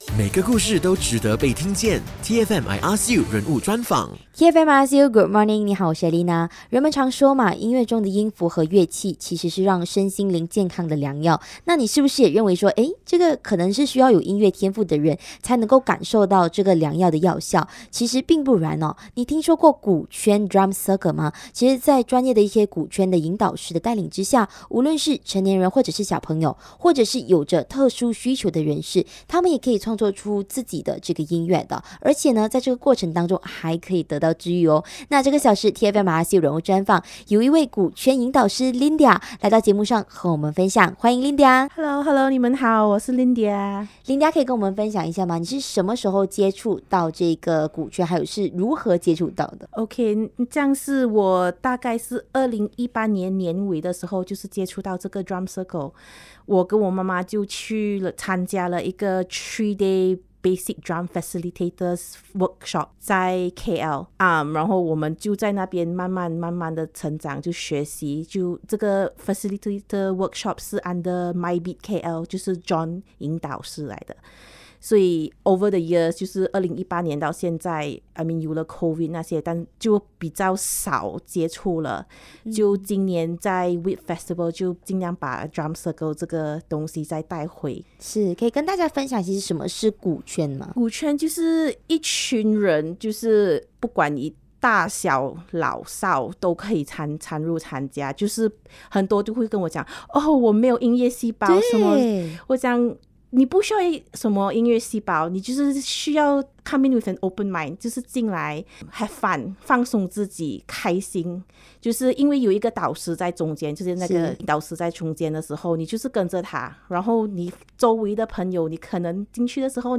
人物专访 鼓圈引导师